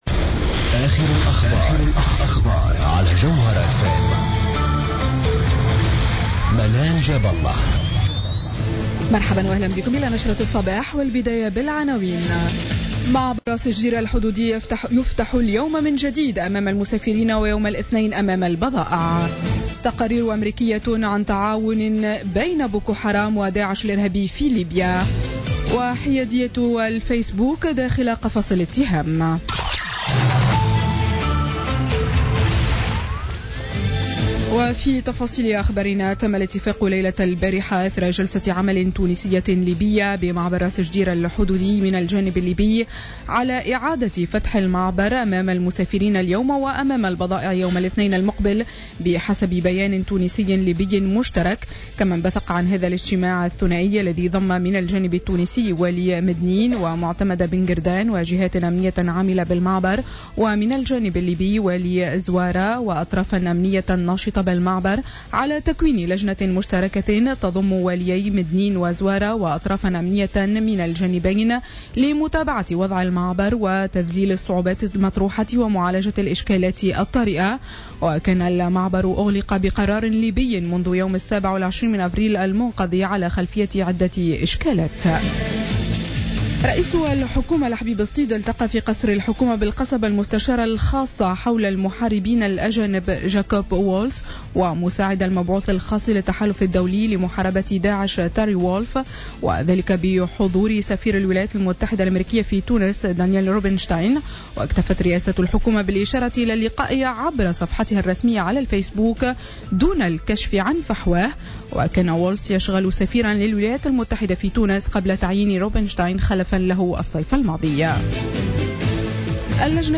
نشرة أخبار السابعة صباحا ليوم السبت 14 ماي 2016